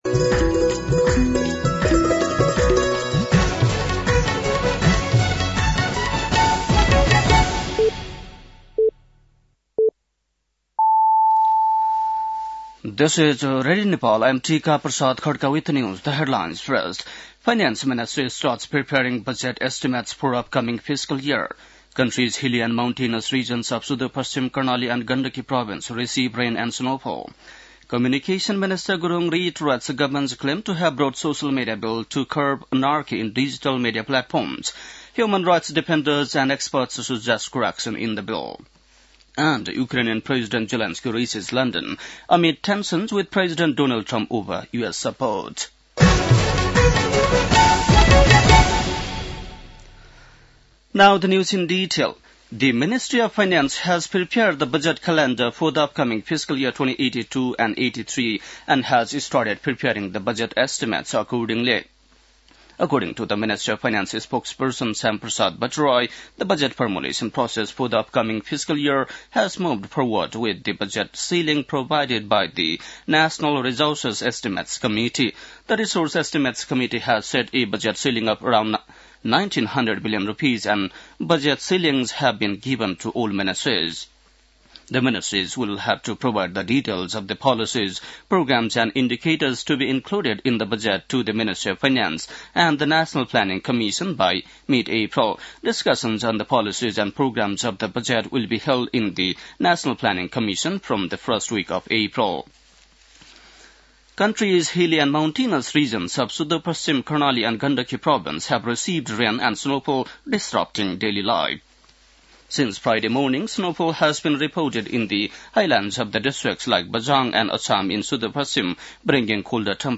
बेलुकी ८ बजेको अङ्ग्रेजी समाचार : १८ फागुन , २०८१